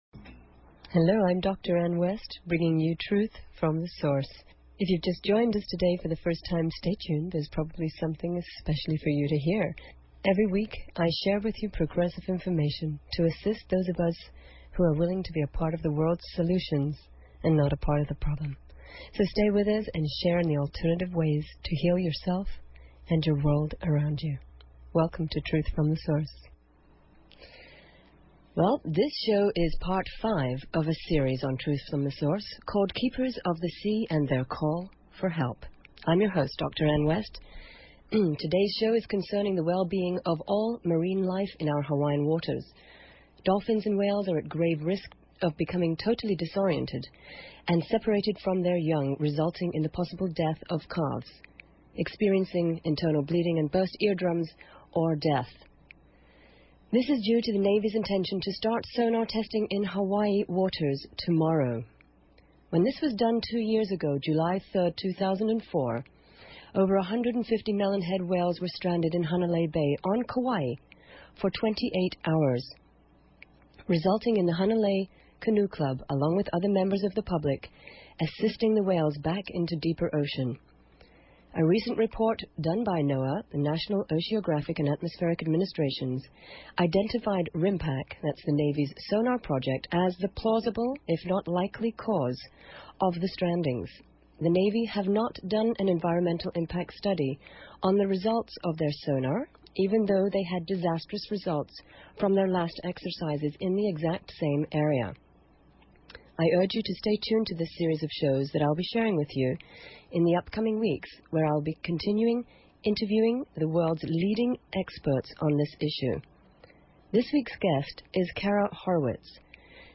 Talk Show Episode, Audio Podcast, Truth_From_Source and Courtesy of BBS Radio on , show guests , about , categorized as